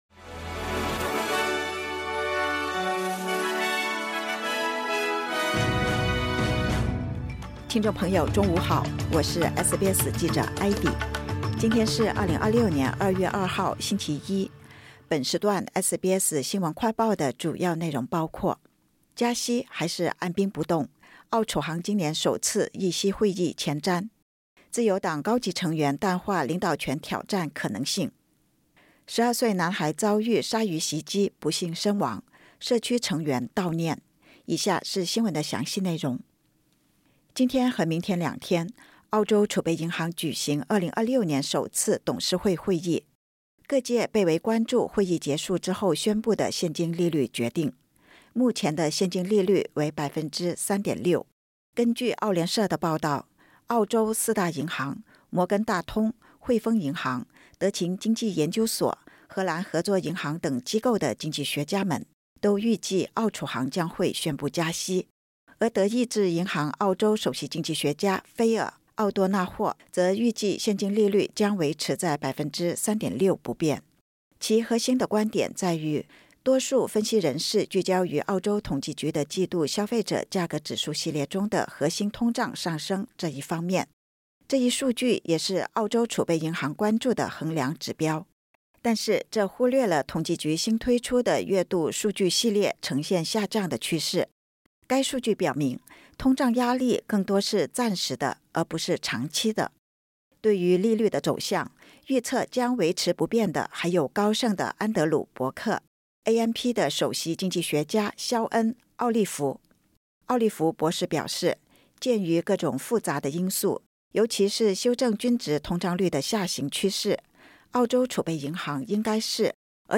【SBS新闻快报】澳储行今年首次议息会议前瞻 加息还是按兵不动？